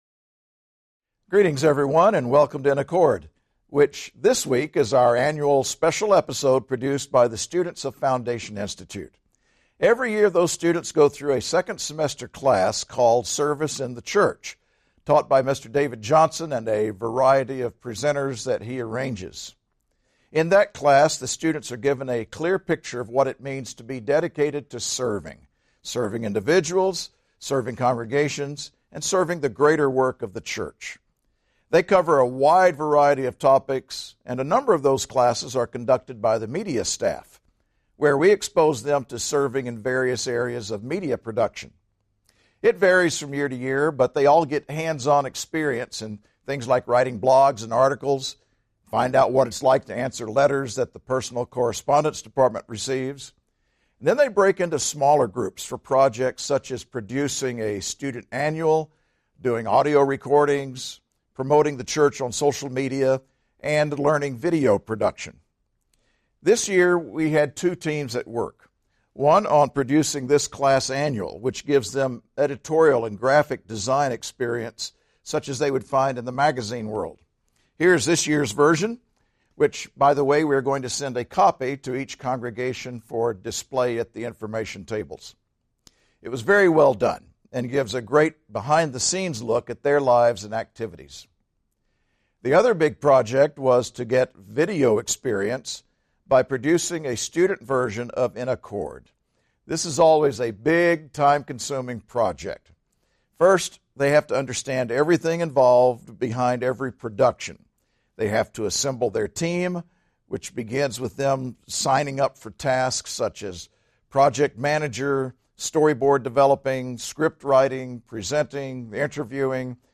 This In Accord is the annual Foundation Institute student-produced program. This year’s students chose to feature FI graduates who are now employed by the Church. In their interviews these employees describe what this has meant for their lives.